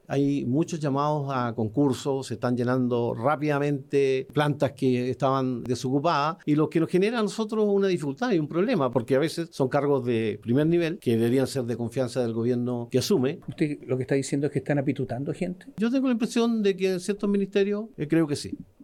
Un nuevo round entre la Oficina del Presidente Electo (OPE) y La Moneda fue el que desató el futuro ministro del Interior, Claudio Alvarado, luego de una entrevista con La Radio, donde reinstaló el debate por los operadores políticos en el Estado.